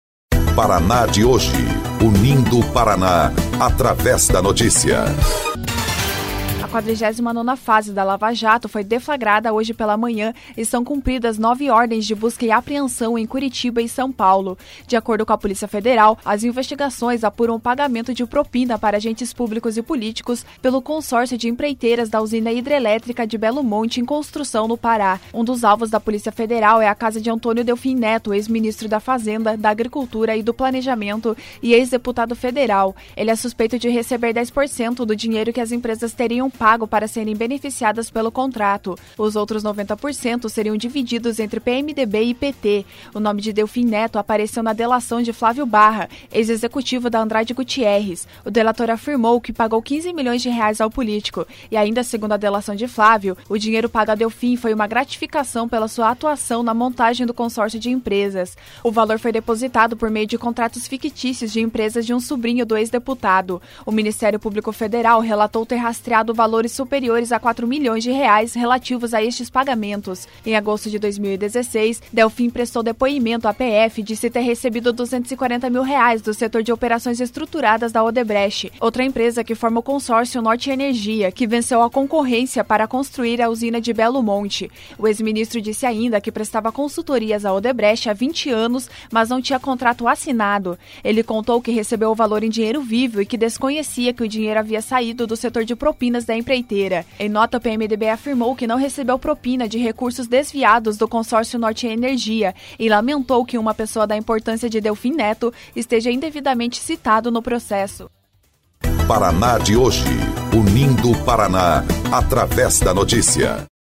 09.03 – BOLETIM – Delfim Neto é alvo de busca e apreensão da 49° fase da Lava Jato